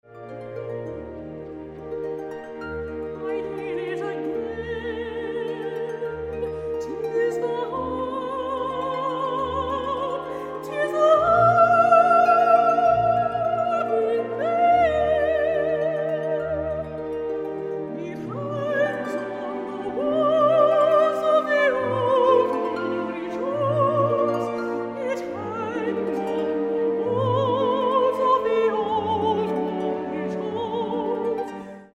Arias from British Operas